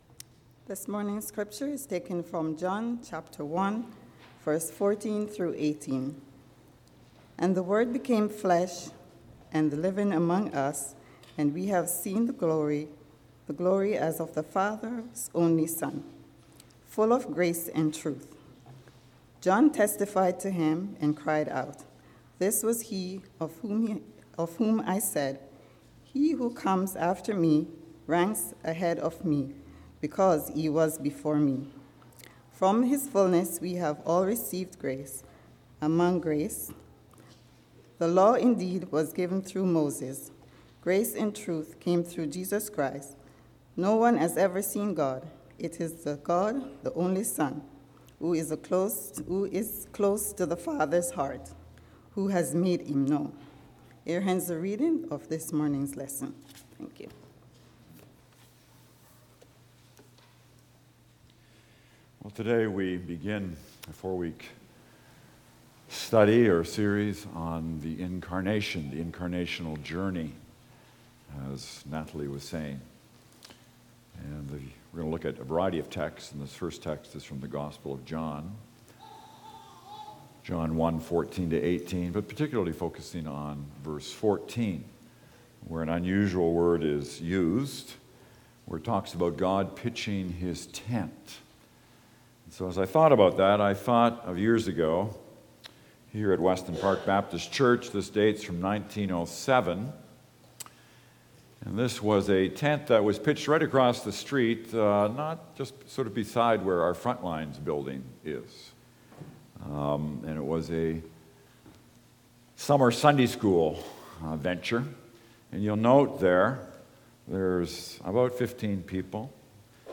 sermon_dec04.mp3